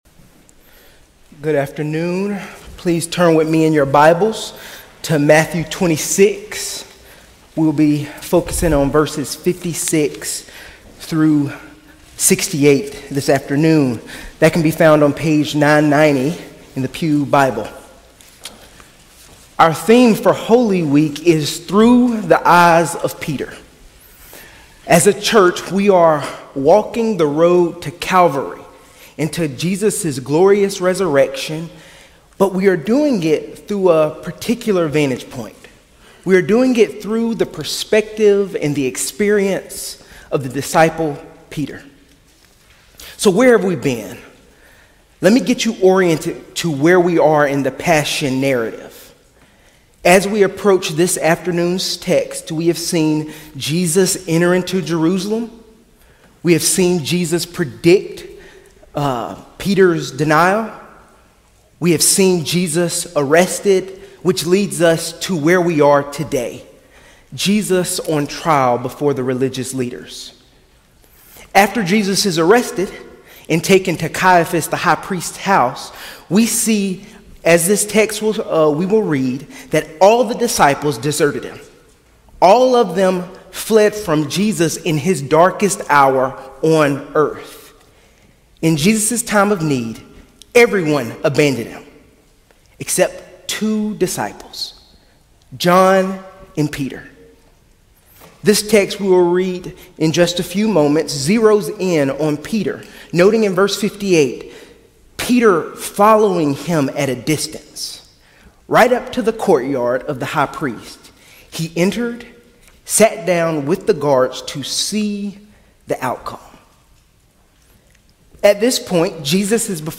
Sermons - First Presbyterian Church of Augusta